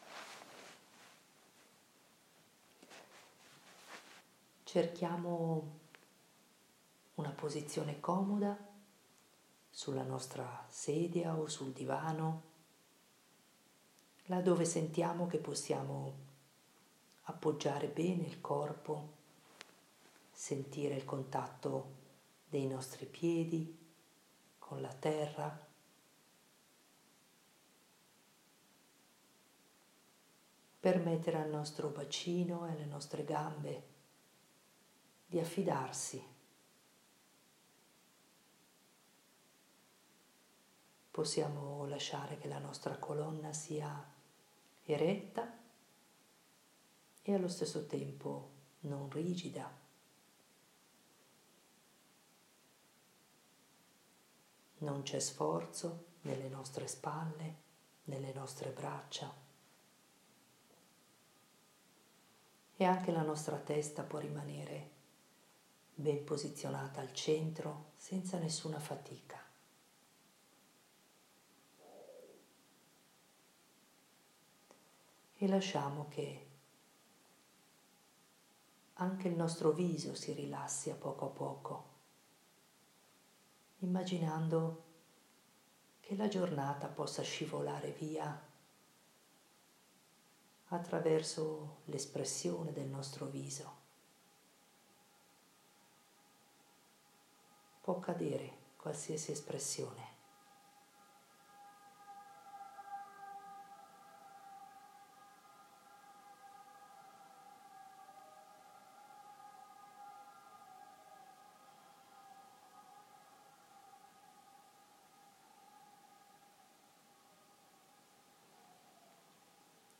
Meditazione della sera